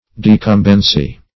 Search Result for " decumbency" : The Collaborative International Dictionary of English v.0.48: Decumbence \De*cum"bence\, Decumbency \De*cum"ben*cy\, n. The act or posture of lying down.